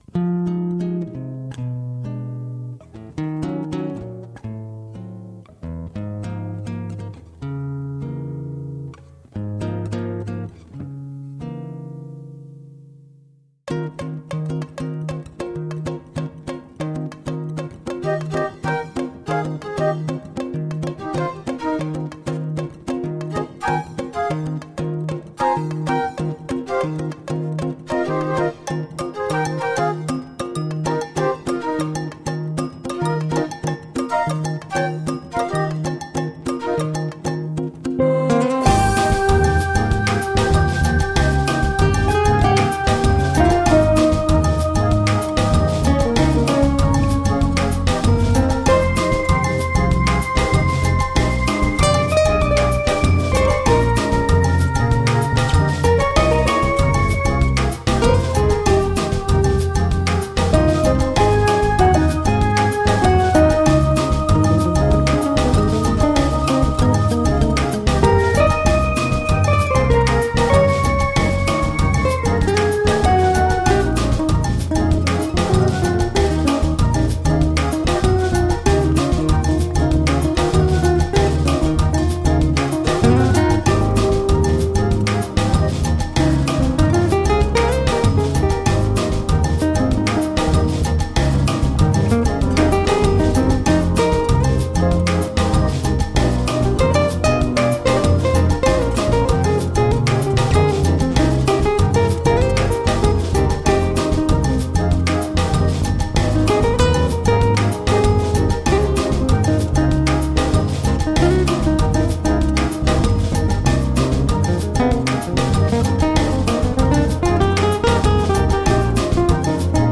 a samba feeling
Tagged as: Jazz, World, Background Mix, World Influenced